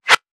weapon_bullet_flyby_13.wav